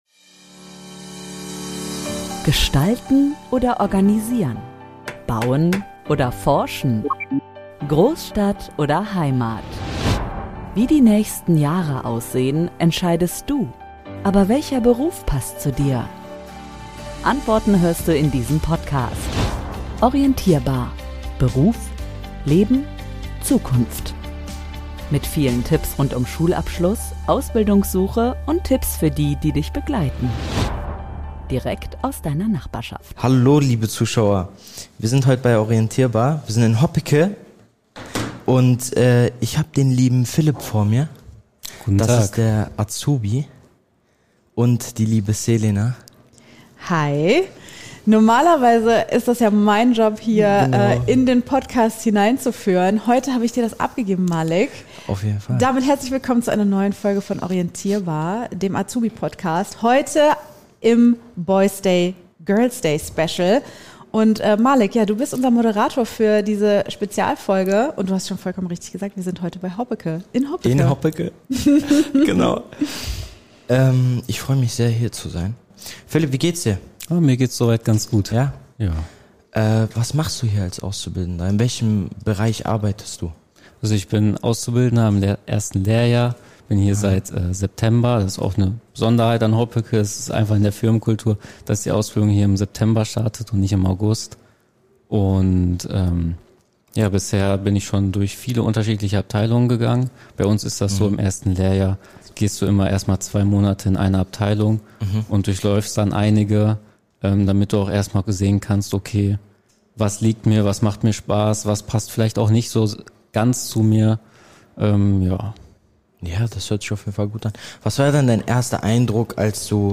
BOYS'DAY Special - zu Besuch bei Hoppecke in Hoppecke ~ ORIENTIERBAR Podcast